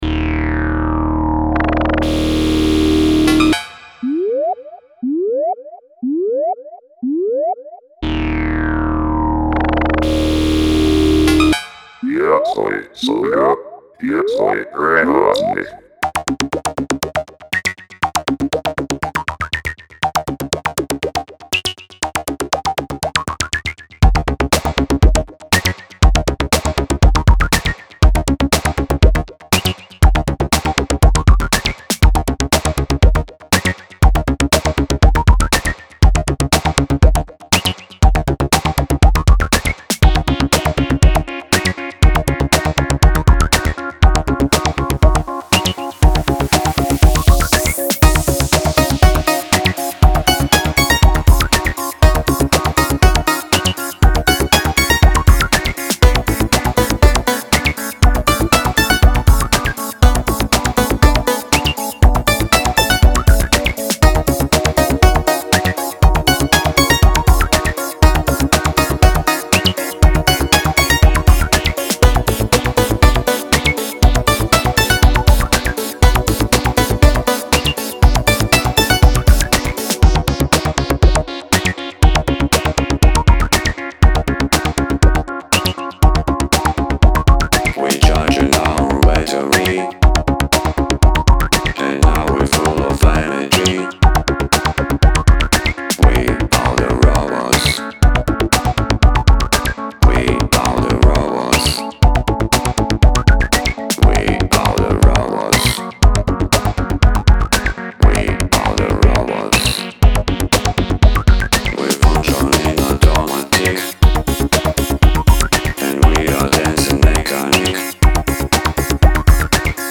Все звучки, включая драмсы накручены с нуля на синтах.